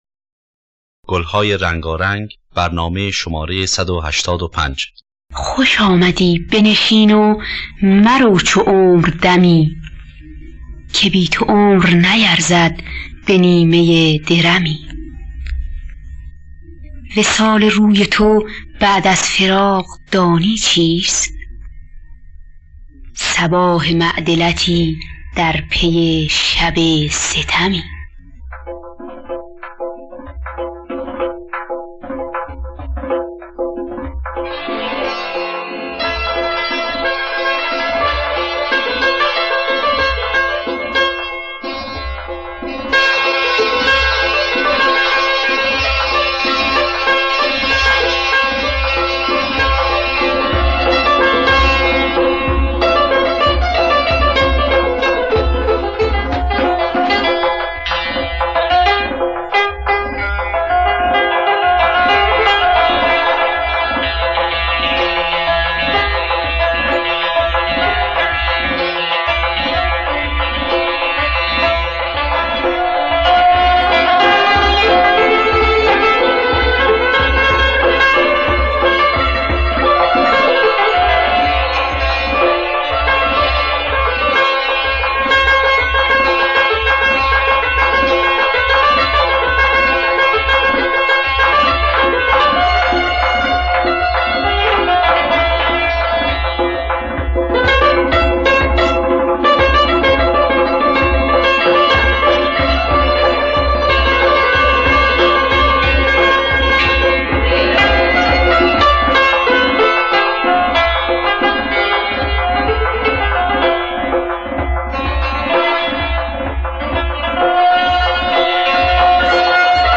گلهای رنگارنگ ۱۸۵ - چهارگاه